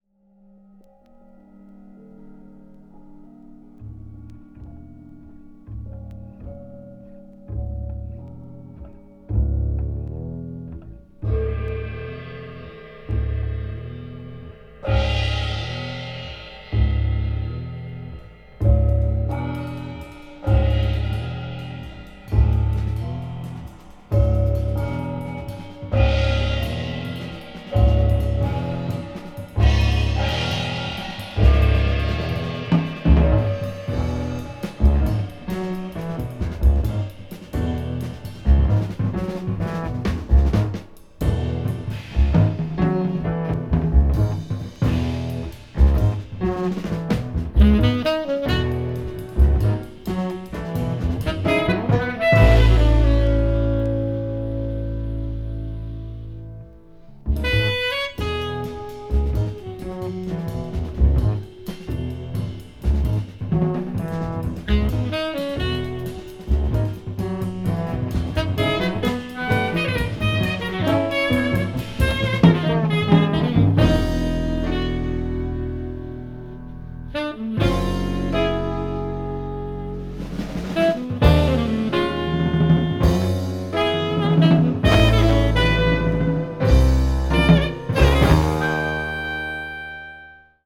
A面は終始一貫したグルーヴがある19分40秒に及ぶセッション。